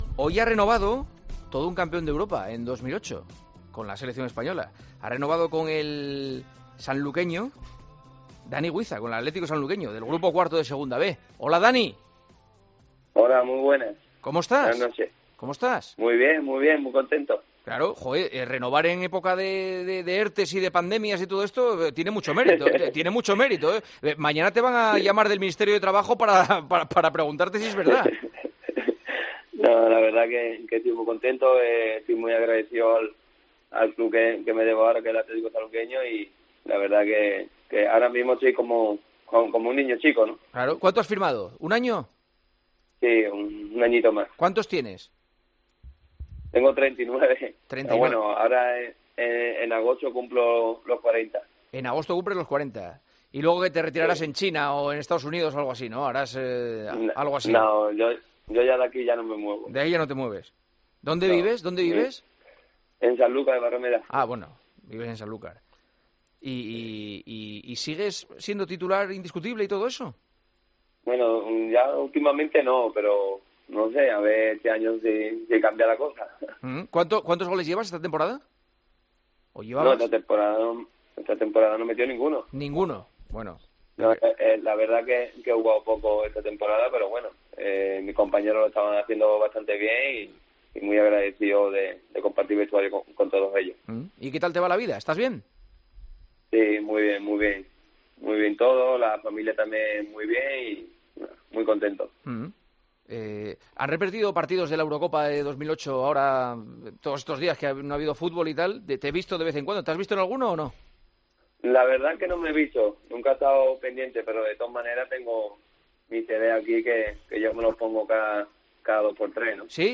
“La foto que me ha enviado todo el mundo es con tres paquetes de cerveza que si lo echas para un mes tienes para dos cervezas diarias”, explica el futbolista entre risas.